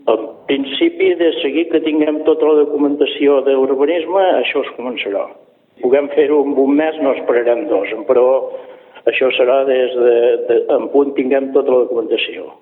La idea d’instal·lar aquest equipament és per la falta de respecte a l’hora de reciclar, com ha explicat Josep Puig, alcalde de Serra de Daró, a Ràdio Capital.